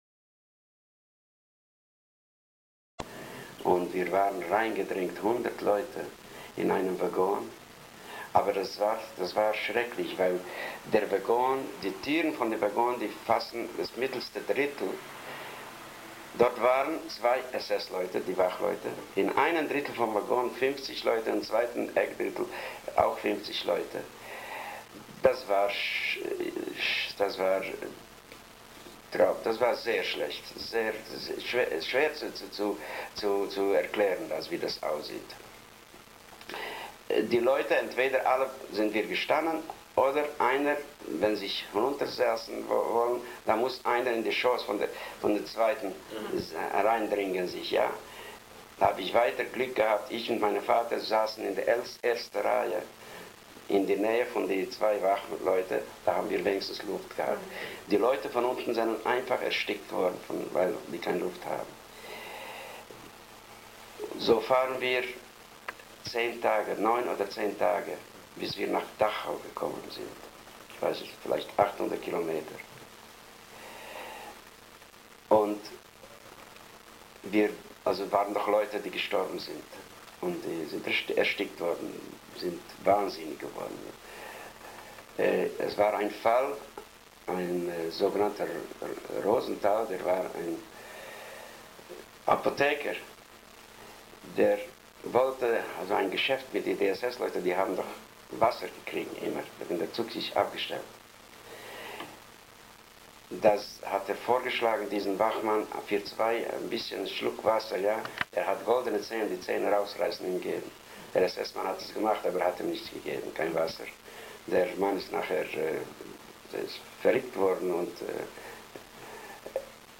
Interview
Auszug aus einem Interview